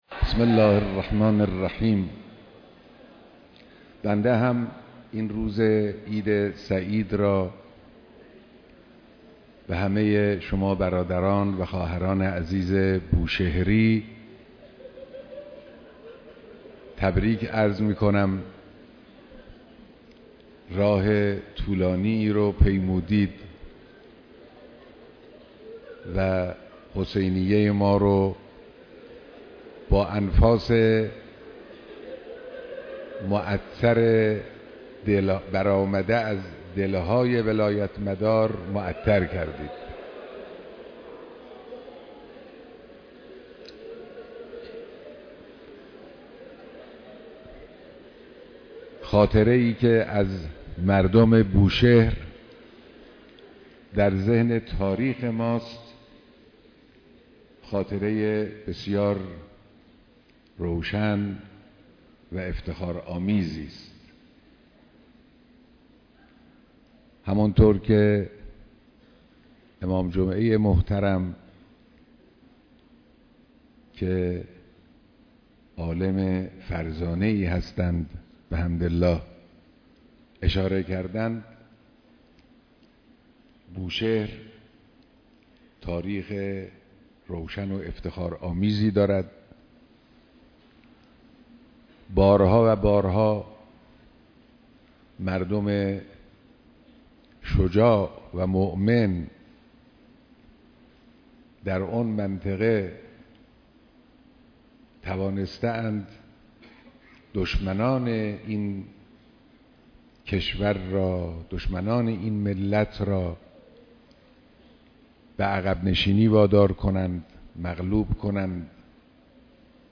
دیدار جمع زیادی از مردم استان بوشهر